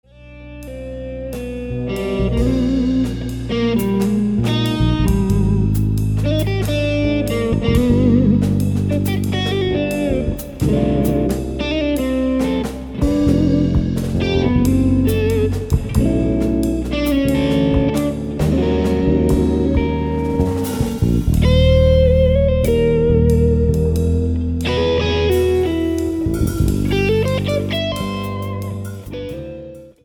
人間技を超えた怪人級プレイの応酬。決して守りに入らない攻撃的なサウンドと予測不可能な曲展開は刺激に満ちあふれている。
ベースが主導して展開するスローテンポのナンバー。